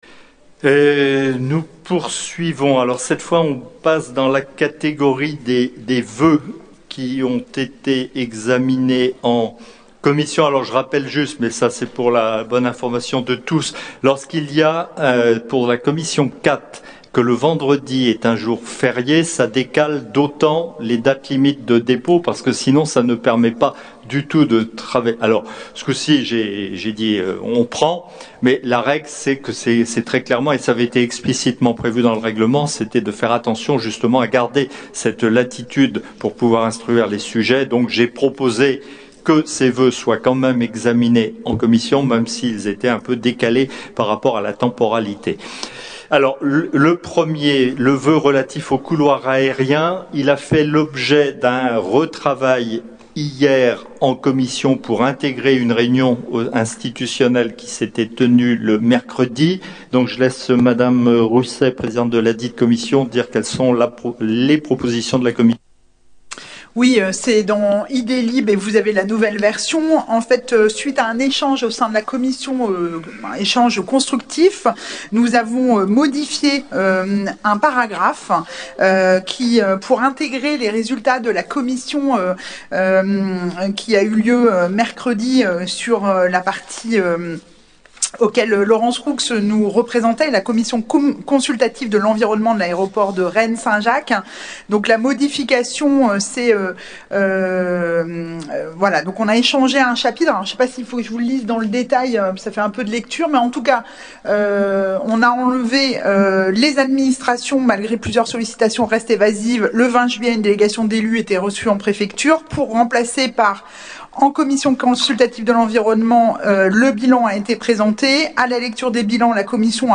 • Assemblée départementale du 17/11/22